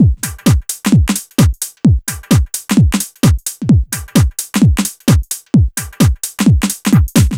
NRG 4 On The Floor 029.wav